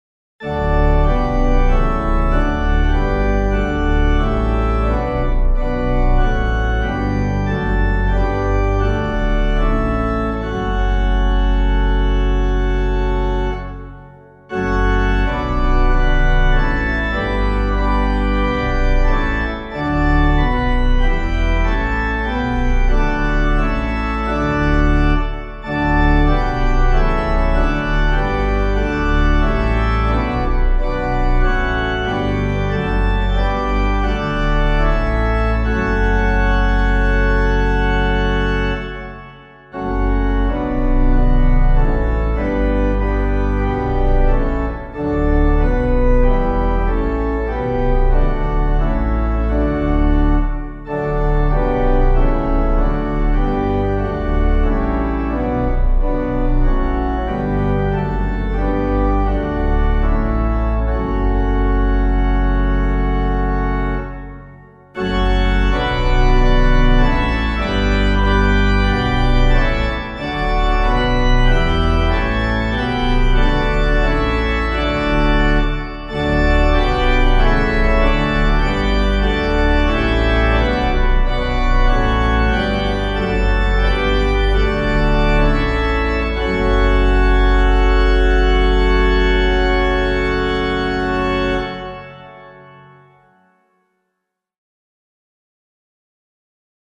Composer:    Chant, mode IV, Sarum, 9th cent.
3 stanzas:
organ